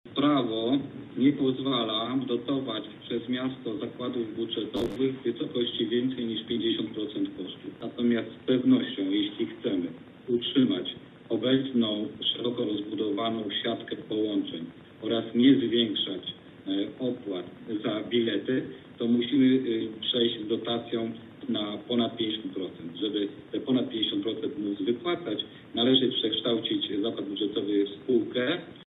Magistrat dzięki tej decyzji będzie mógł przekazywać MZK więcej pieniędzy. Tłumaczy Krzysztof Kaliszuk, wiceprezydent Zielonej Góry: